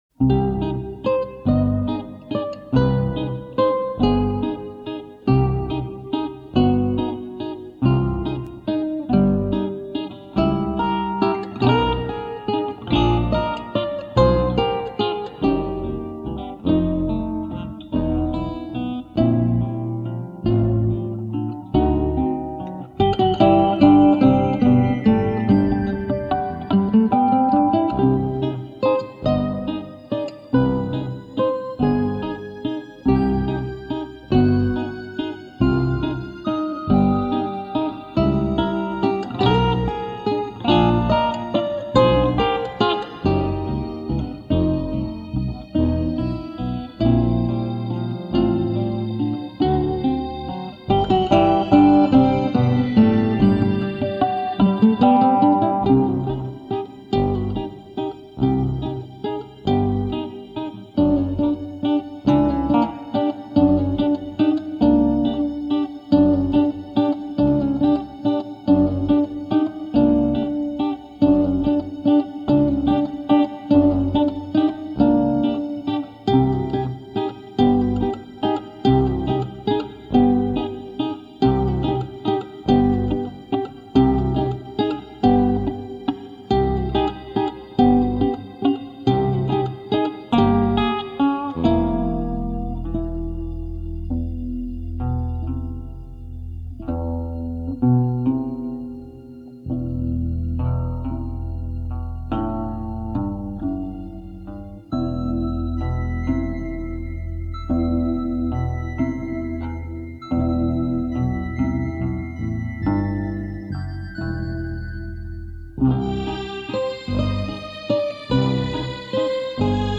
Acoustic and Electric guitars, keyboards and programming